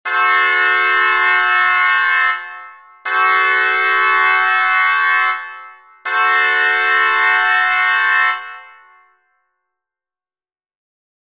And just to drive the point home, how these three diminished iv chords sound, in sequence:
For the curious, I used Musescore2 to create these scale samples, due to it’s easy-to-apply tuning adjustments on notes, with the bagpipe sound specifically because it did not have vibrato.